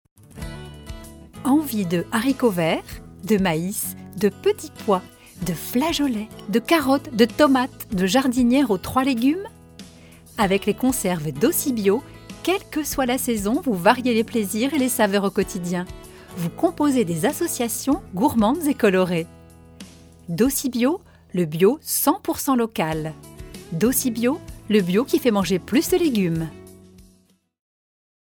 Comédienne et Comédienne Voix
Sprechprobe: Werbung (Muttersprache):